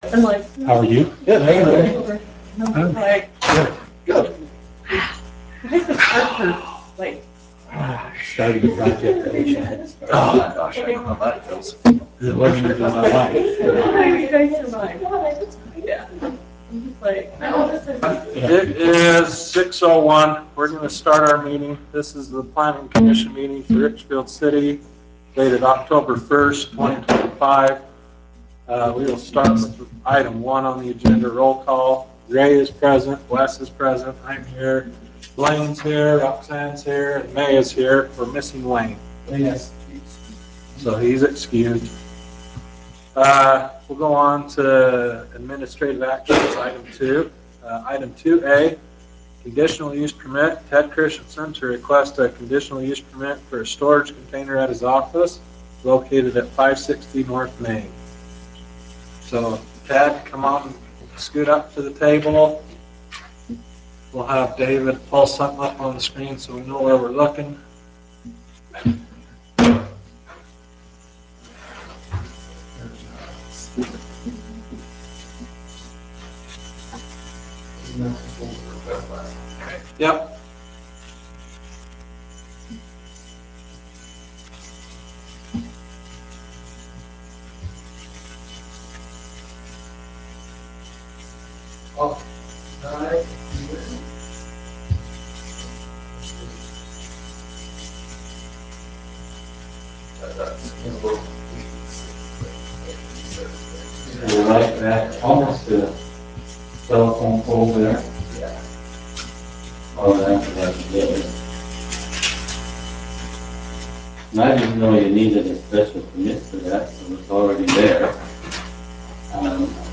Richfield Planning Commission Planning Commission Meeting for Oct. 1, 2025